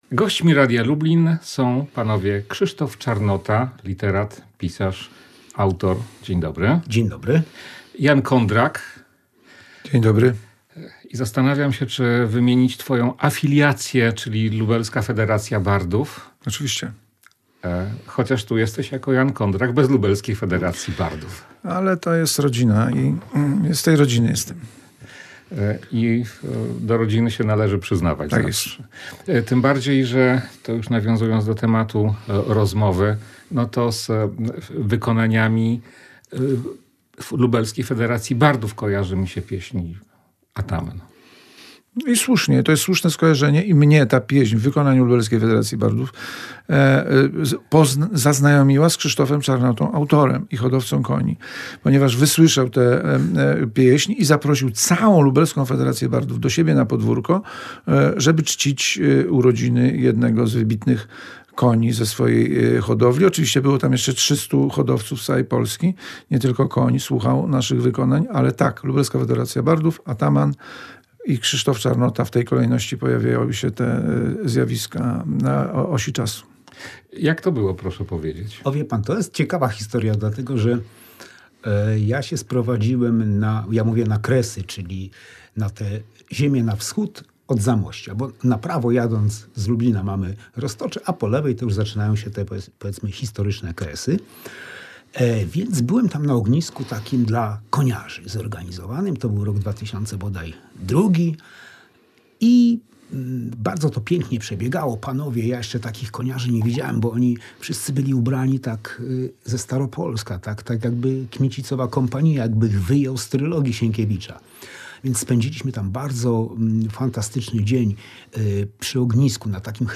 18.01.2026 Tylko po polsku - rozmowa